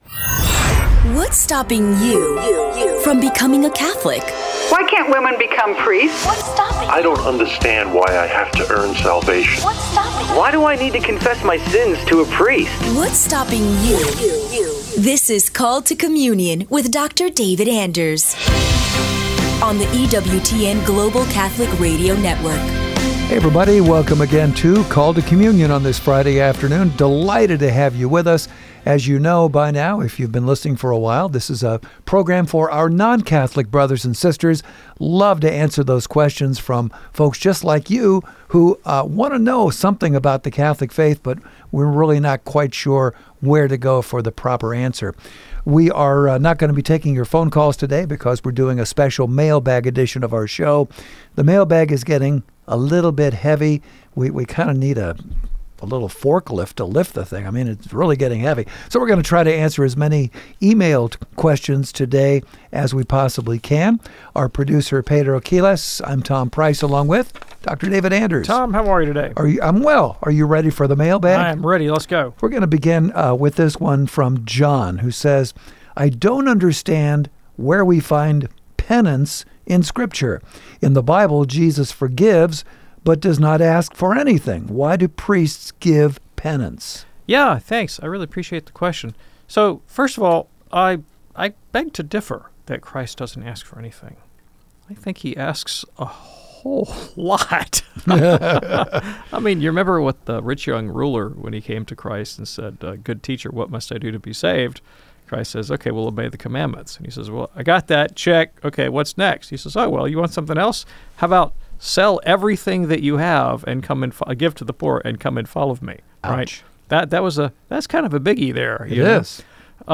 public ios_share Called to Communion chevron_right The Right to Self Defense Jan 26, 2026 A lively mailbag-style discussion jumps between repentance and penance in Scripture, debates over second repentance and apostasy, and how Church teaching and personal conscience interact. They probe patripassianism and the Incarnation, defend limits of physical self-defense, and unpack Gospel timeline puzzles and Revelation geography. 50:26 forum Ask episode play_arrow Play